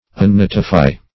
Meaning of unnotify. unnotify synonyms, pronunciation, spelling and more from Free Dictionary.
Search Result for " unnotify" : The Collaborative International Dictionary of English v.0.48: Unnotify \Un*no"ti*fy\, v. t. [1st pref. un- + notify.]